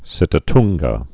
(sĭtə-tngə)